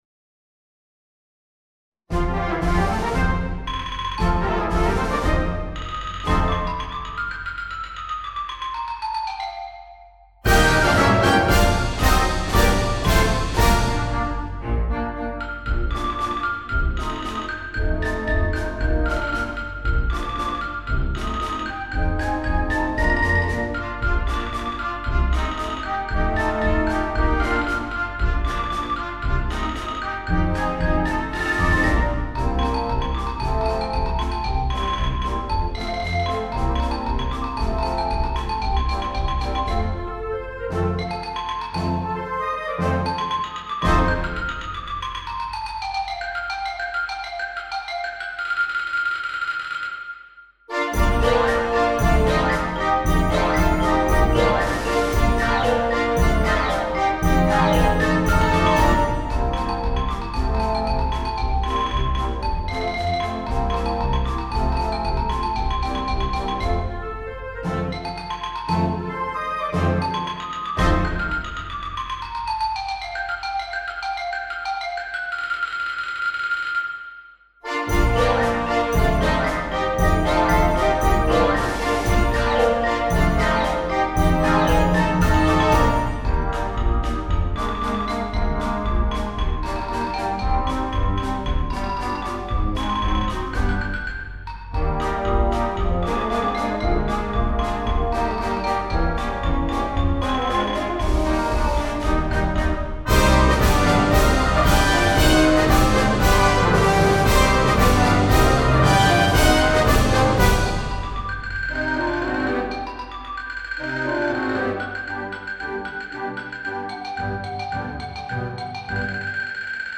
Noten für Blasorchester.
• Polka für Xylophon und Blasorchester